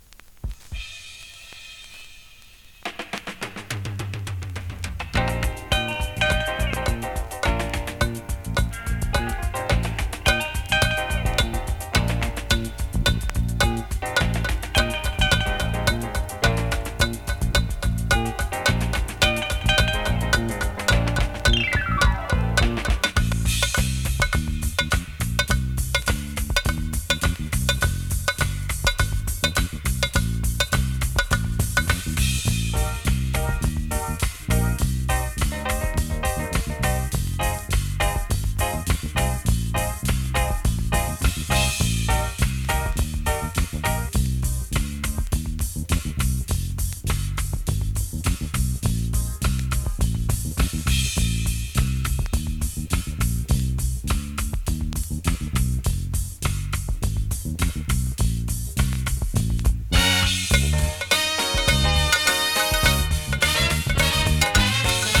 型番 SIDE 2:VERSION/VG++ ※少しセンターズレ有
スリキズ、ノイズ比較的少なめで